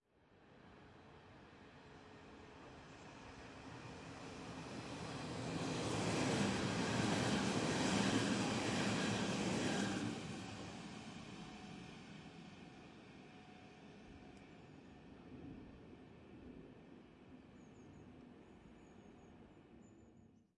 德国柏林Priesterweg的火车经过 " S Bahn城市火车经过的近距离 2
描述：SBahn城市列车通过。在铁路轨道上方的桥上听到了。 2016年9月在柏林Priesterweg以Zoom HD2录制成90°XY
Tag: 铁路 火车 城市列车 铁路 铁路 铁路 火车 电动火车 轨道 现场记录 轻轨 乘客列车